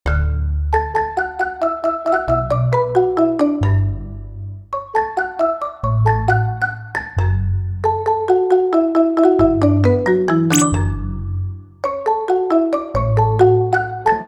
Забавный мотивчик на ксилофоне
Метки: красивые, без слов, прикольные,